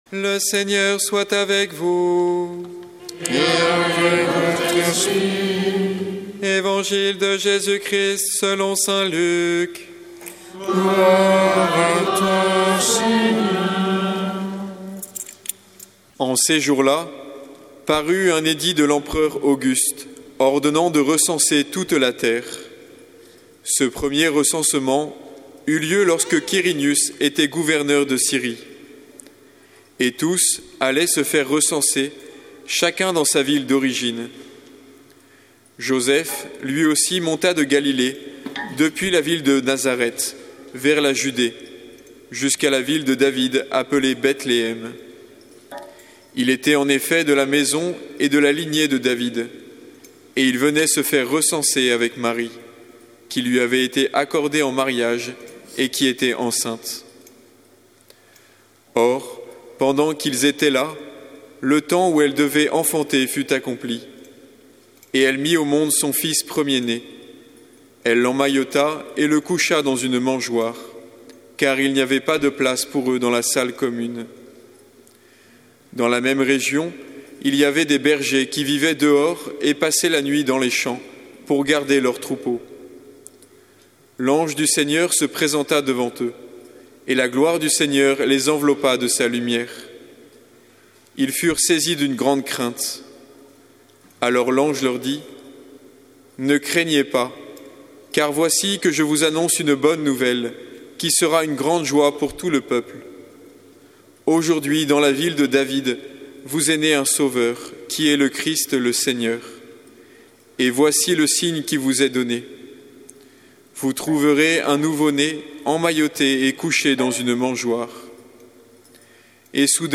Paroisse St Charles de Foucauld | Nativité du Seigneur (messe de la nuit) — Année A
Évangile de Jésus Christ selon saint Luc avec l'homélie